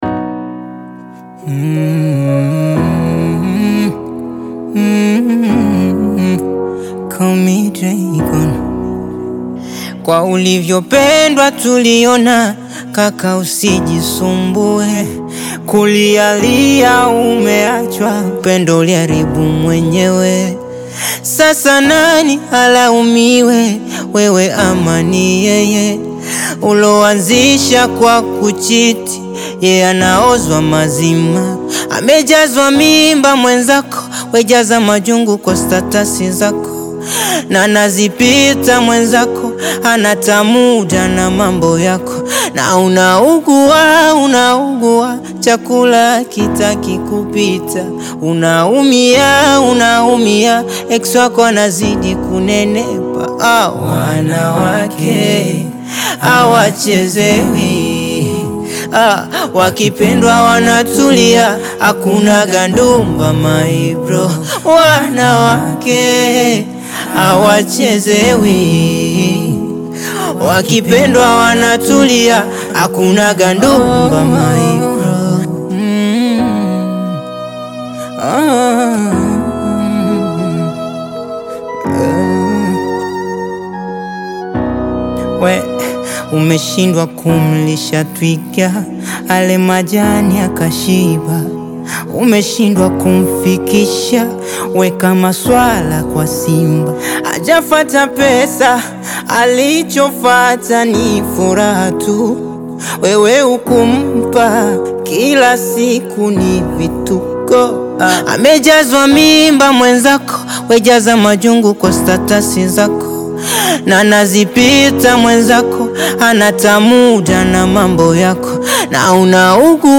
Tanzanian Bongo Flava artist, singer and songwriter
Bongo Flava